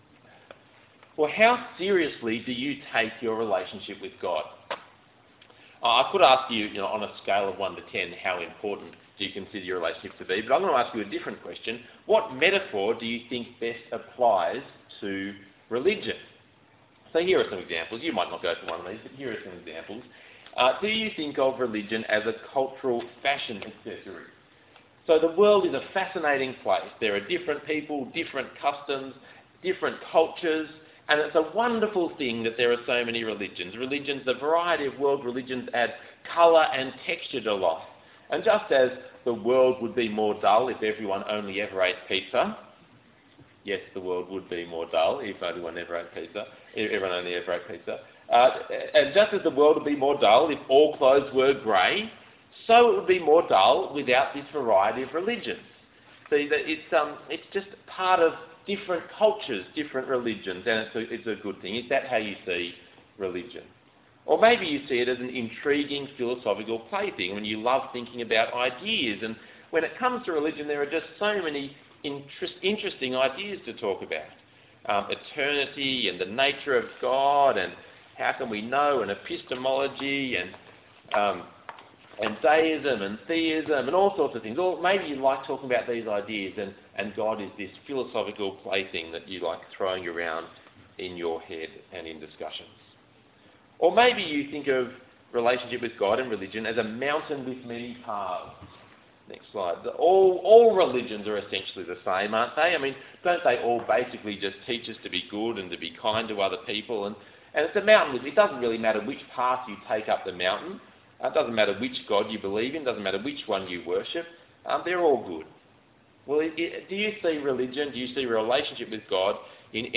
Passage: Hosea 1:1-2:1 Talk Type: Bible Talk